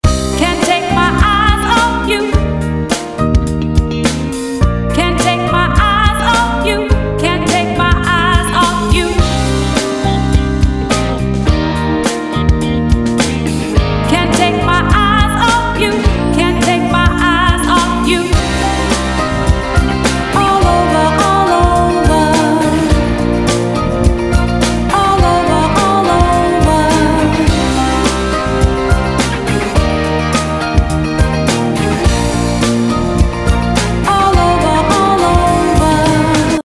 … und nun mit dem Preset Gentle Comp, bei dem ich die Thresholds angepasst und den Air-Mode eingeschaltet habe.
Wunderbar, wie der Drawmer 1973 Instrumente und Gesang zusammenführt, Wärme hinzufügt und den Eindruck räumlicher Tiefe erzeugt; das alles recht unauffällig – in dem Sinn, dass man die Kompression nicht als Effekt wahrnimmt.